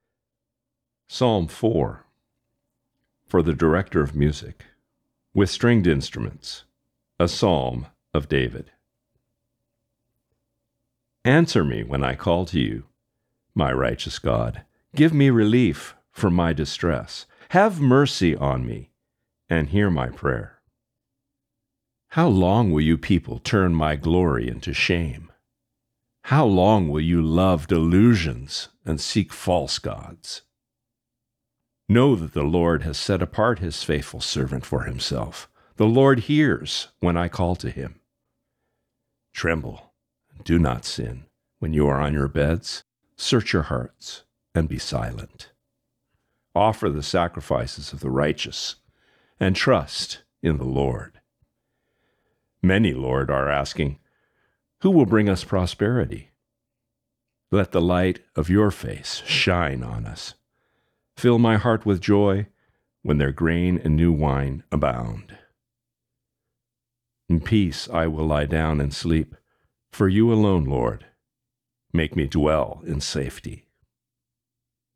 psalm-4-remix1final.mp3